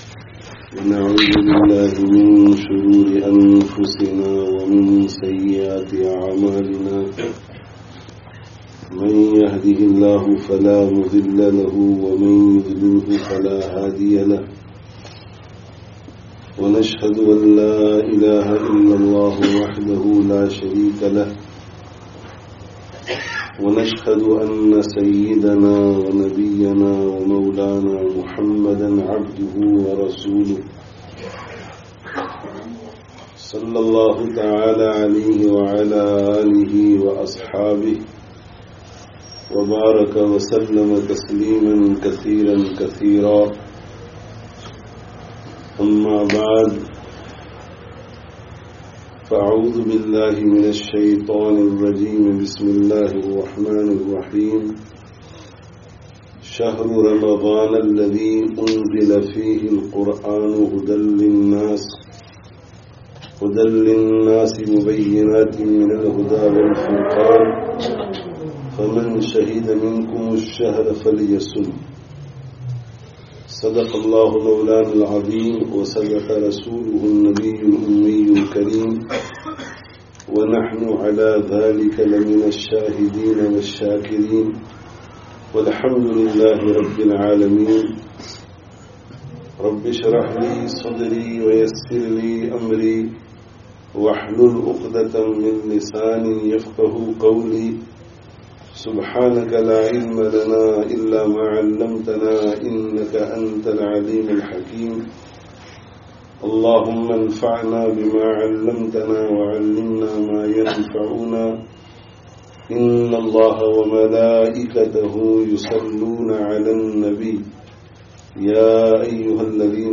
Youth Programme (Upton Lane Masjid, London 20/04/19)